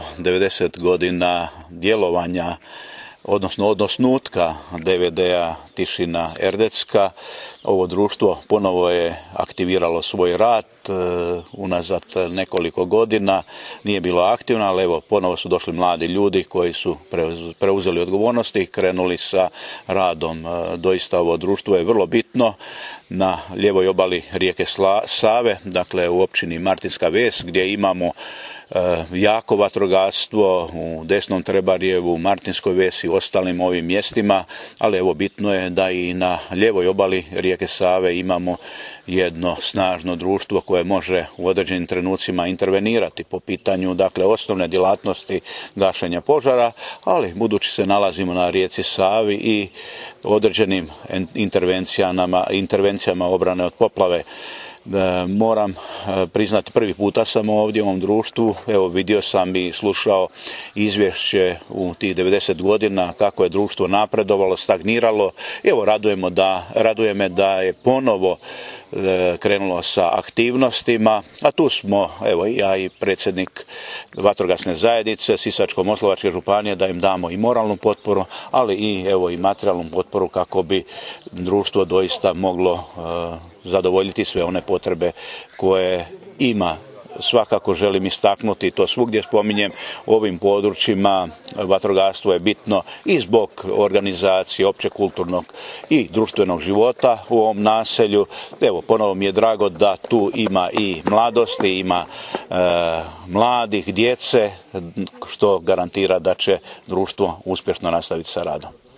Poslušajte izjavu koju je župan Ivo Žinić dao u Tišini Erdedskoj: